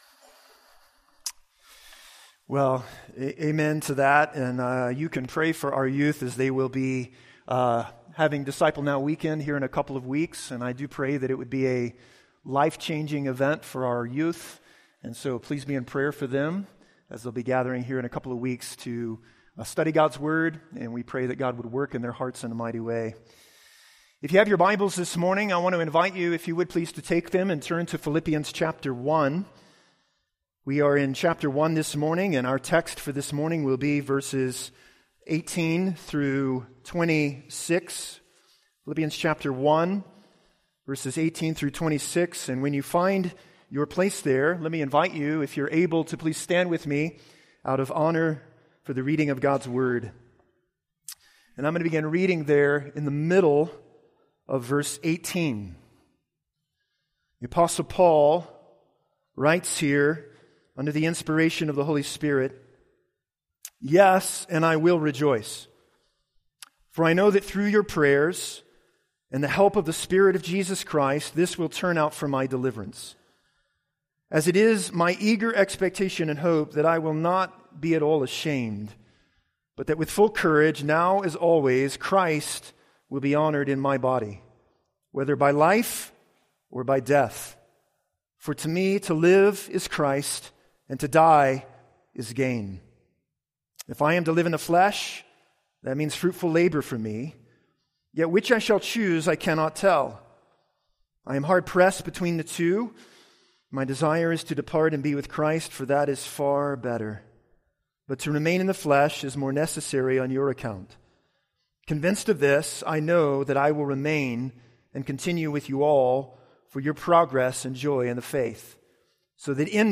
A message from the series "Special Sermons."